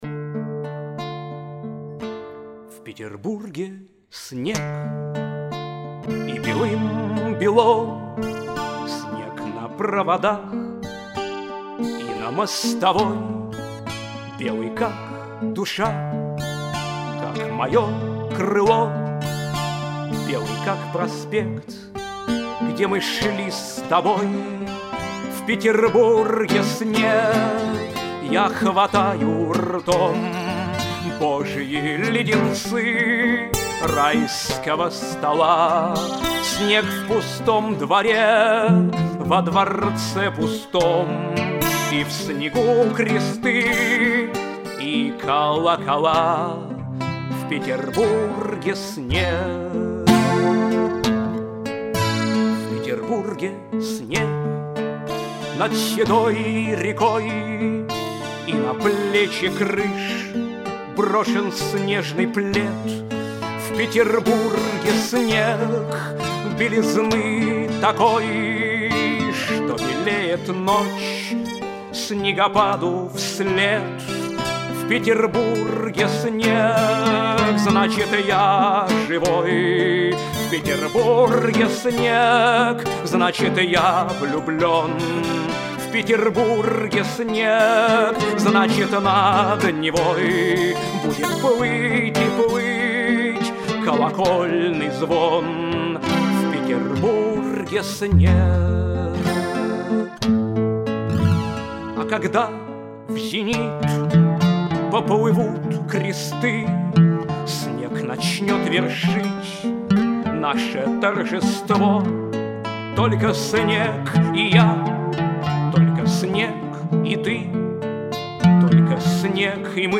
Авторская песня
гитара